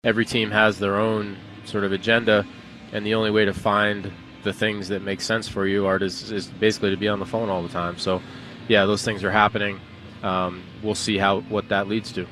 On The Ben Cherington Show on WCCS yesterday, the Pirates’ general manager said he is listening to offers with the MLB trade deadline approaching on July 31st.  Cherington says the call volume increased rapidly last week during the All-Star break.